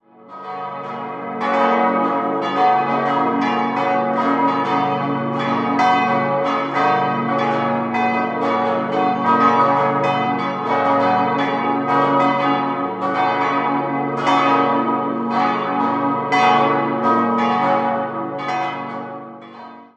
5-stimmiges Geläute: b°-des'-es'-ges'-as' Die mittlere Glocke wurde 1796 von Joseph Spannagl in Straubing gegossen, alle übrigen stammen von Johann Hahn (Landshut) aus dem Jahr 1949.